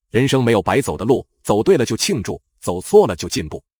效果演示(不做任何后期处理！所听即所得，语速可调)